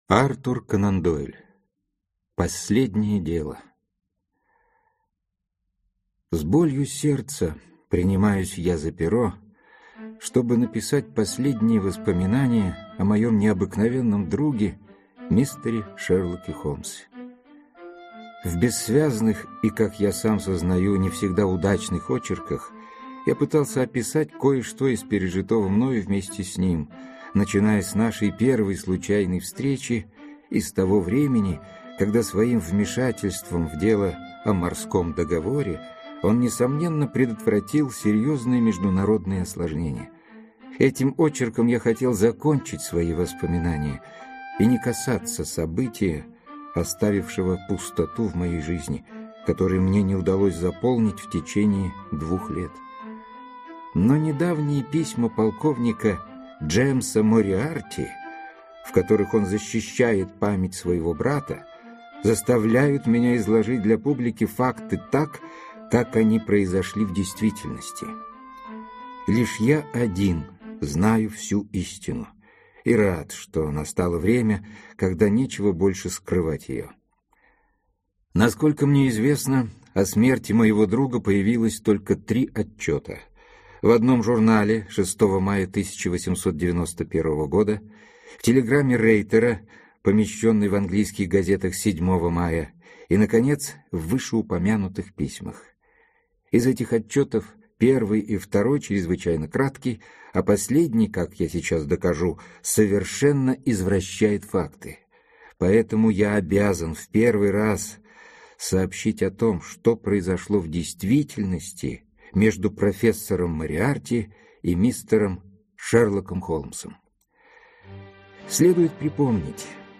Аудиокнига Рассказы о Шерлоке Холмсе | Библиотека аудиокниг
Aудиокнига Рассказы о Шерлоке Холмсе Автор Артур Конан Дойл Читает аудиокнигу Борис Плотников.